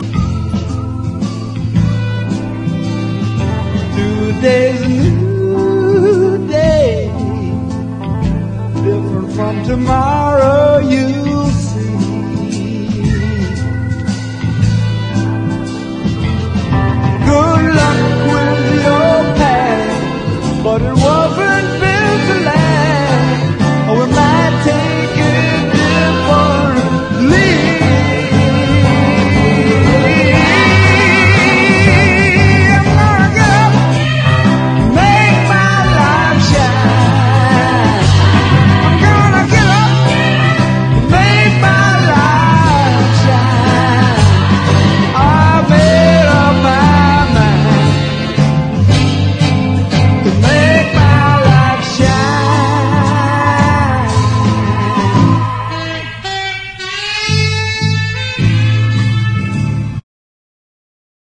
ROCK / 80'S/NEW WAVE. / POWER POP / REGGAE / POST PUNK